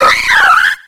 Cri de Férosinge dans Pokémon X et Y.